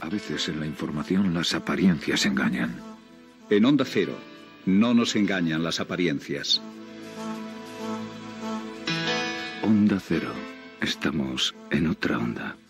Promoció Onda Cero amb la veu de Luis del Olmo